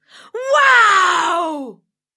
Звуки мультяшного голоса
Звук Вау